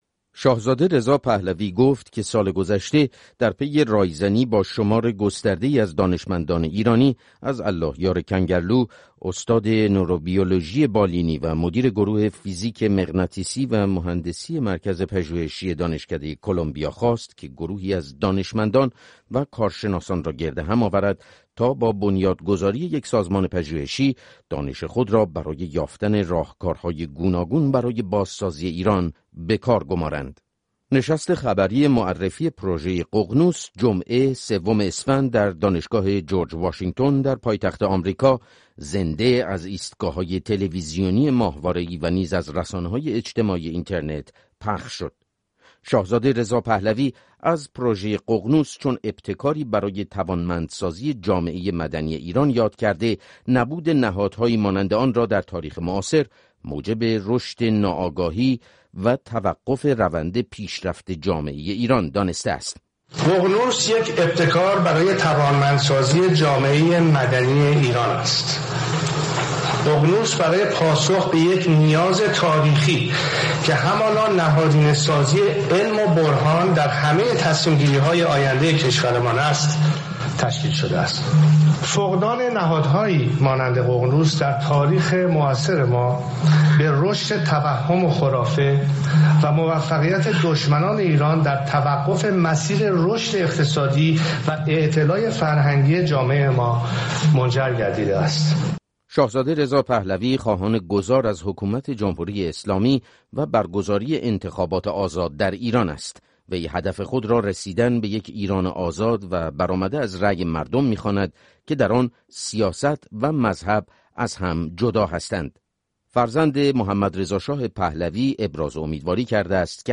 شاهزاده رضا پهلوی با اعلام پروژه‌ای پژوهشی بنام «ققنوس» از برنامه‌ها برای بازسازی ایران در دوران پس از جمهوری اسلامی سخن گفته است. فرزند محمدرضاشاه پهلوی روز جمعه سوم اسفند در یک نشست خبری در دانشگاه جورج واشنگتن هدف پروژه را بکارگیری دانش پژوهشگران و کارشناسان ایرانی برای نوسازی کشور خواند.